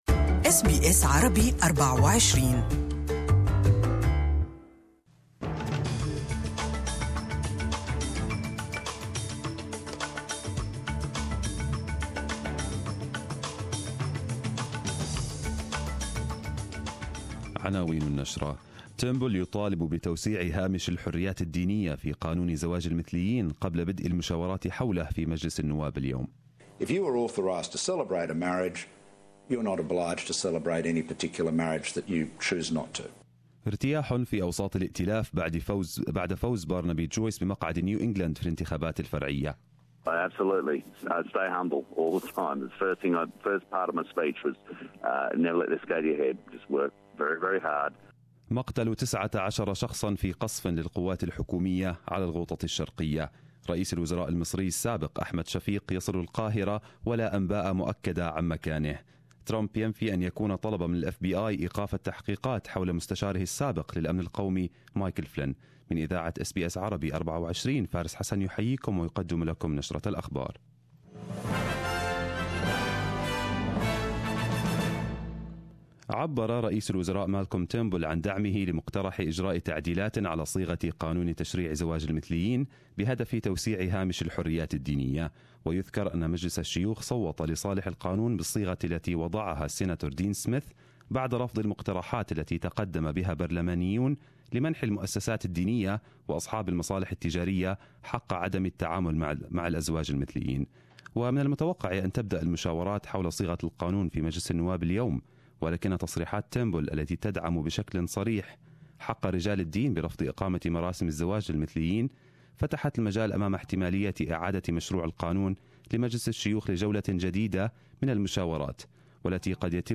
Arabic News Bulletin 04/12/2017